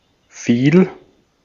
Ääntäminen
Ääntäminen : IPA: /fiːl/ Haettu sana löytyi näillä lähdekielillä: saksa Käännöksiä ei löytynyt valitulle kohdekielelle. Fiel on sanan fallen imperfekti.